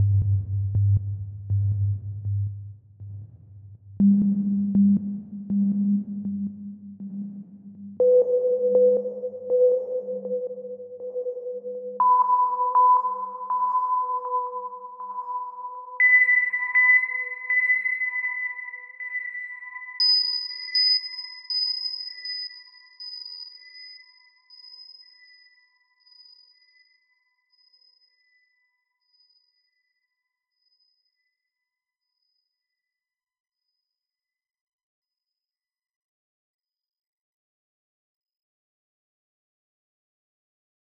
Atlas - STest1-PitchPulse-Left-100,200,500,1000,2000,5000.flac